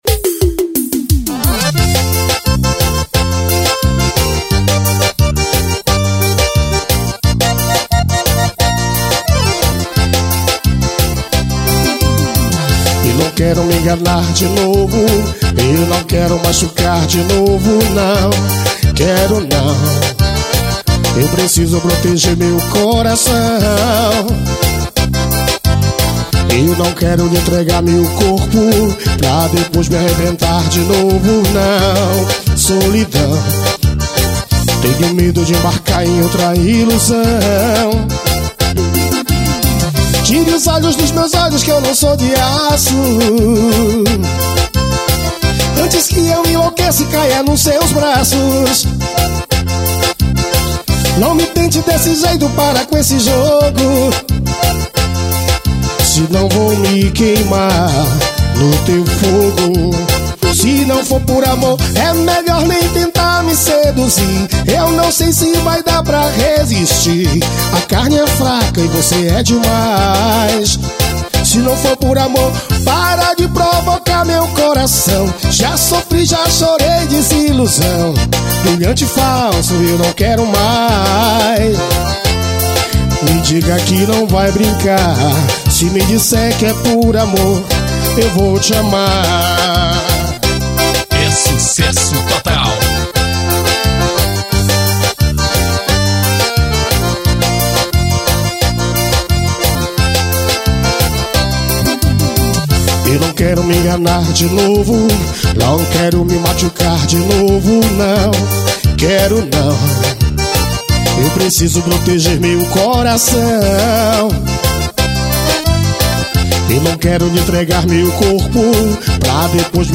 AO VIVO EM GOIANIA.